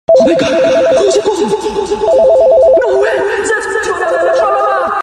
Fool friends with ultra-realistic call